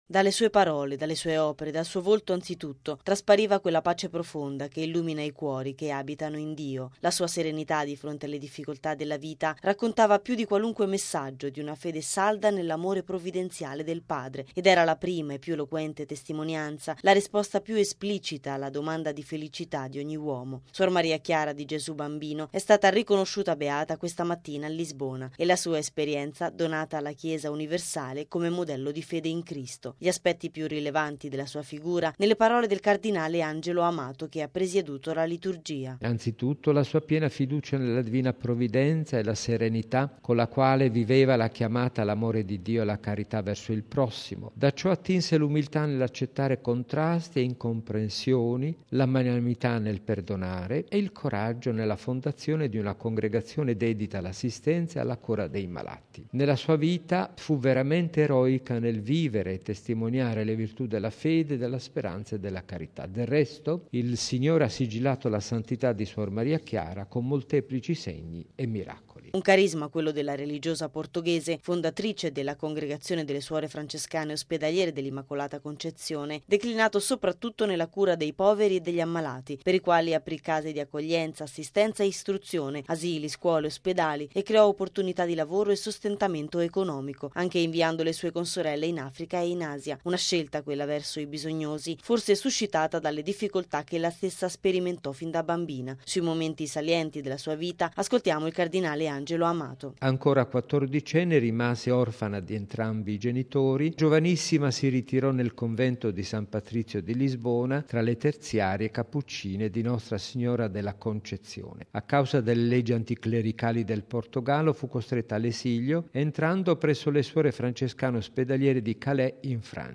Suor Maria Chiara di Gesù Bambino è stata riconosciuta Beata questa mattina a Lisbona, e la sua esperienza donata alla Chiesa universale come modello di fede in Cristo. Gli aspetti più rilevanti della sua figura nelle parole del cardinale Angelo Amato, che ha presieduto la liturgia:
Sui momenti salienti della sua vita ascoltiamo il cardinale Amato: